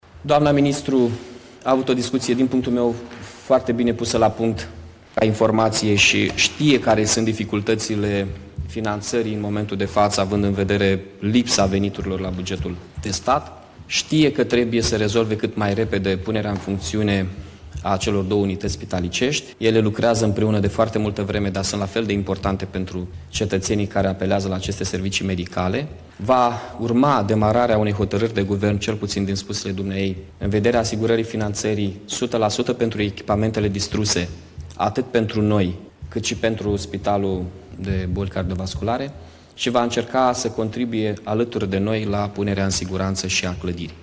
Primarul Mihai Chirica a mai spus că a discutat cu ministrul sănătăţii, Sorina Pintea, care l-a asigurat că va fi despusă la Guvern o hotărâre pentru alocarea finanţării pentru achizionarea echipamentelor necesare Institutului de Boli Cardiovasculare: